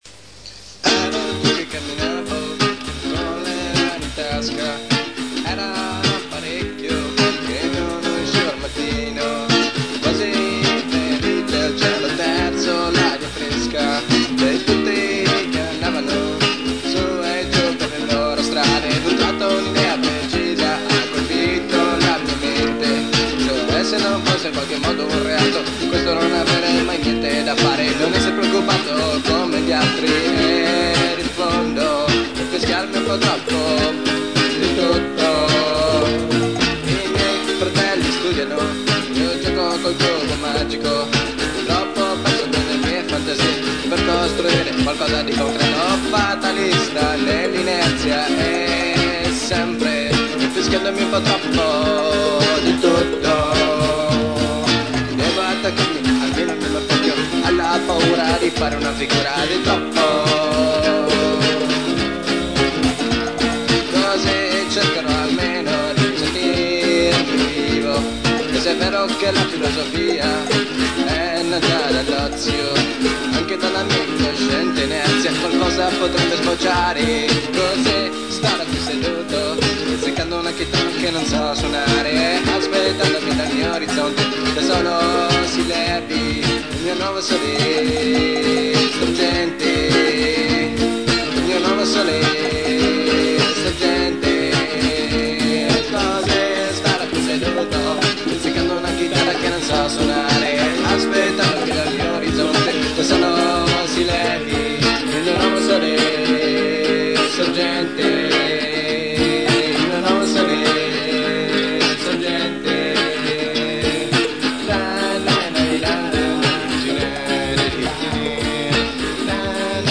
voce, chitarra ritmica
basso, percussioni, voce
Registrazione elaborata separatamente dagli autori nel 1992